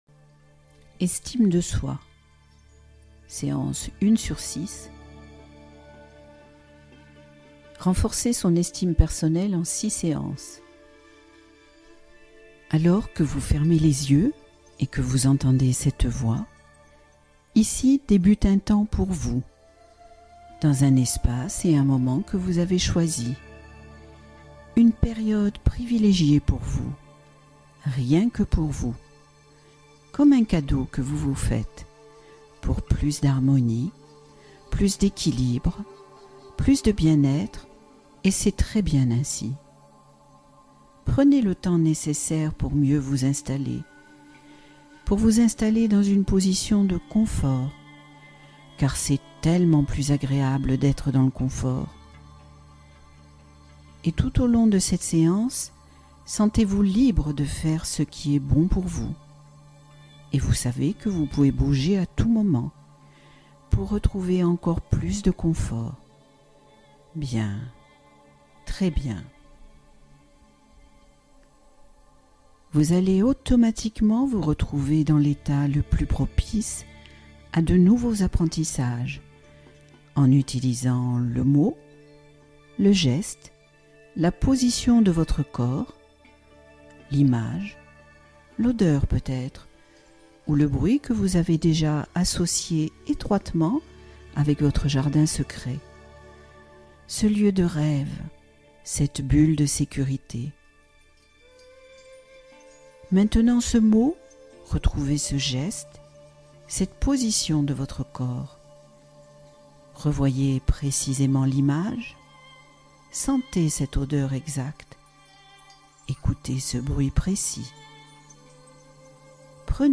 Endormissement accéléré — Méditation pour basculer rapidement dans le sommeil